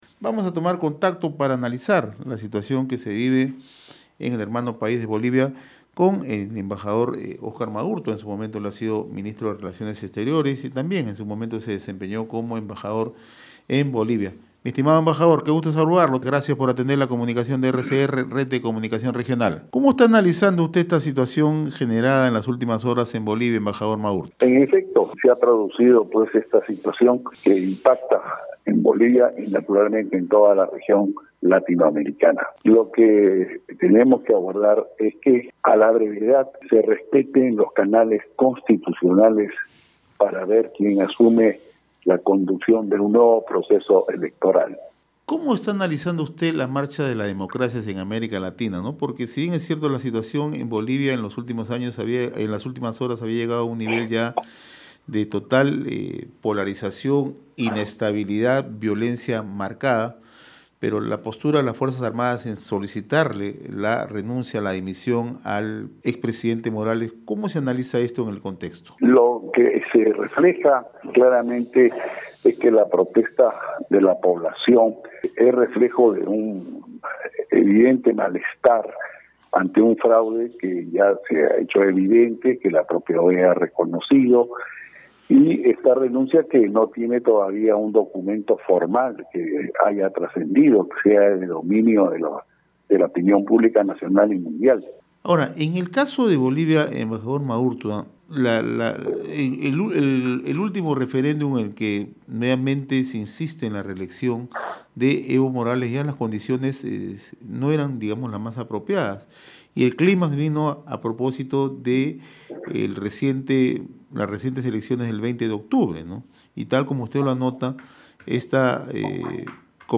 Esta renuncia no tiene un documento formal que sea de dominio de la opinión pública nacional y mundial”, indicó el diplomático a través de Red de Comunicación Regional (RCR).